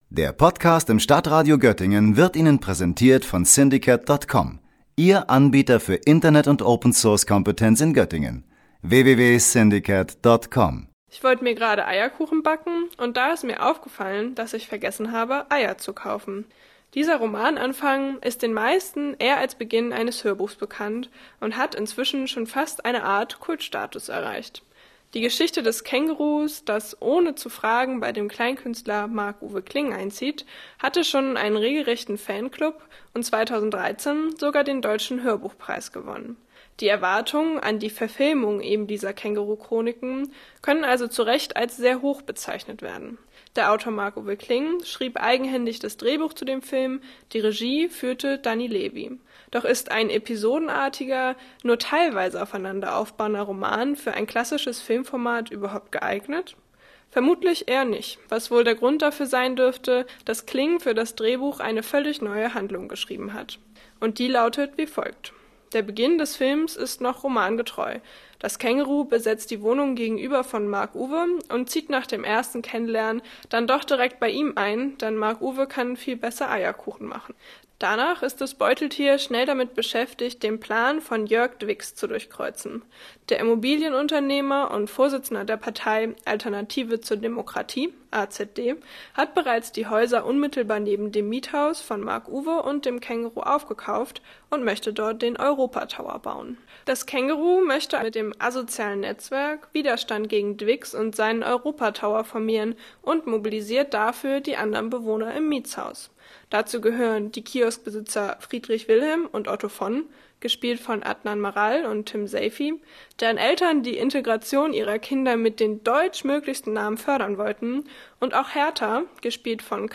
Beiträge > Filmrezension „Die Känguru Chroniken“ - StadtRadio Göttingen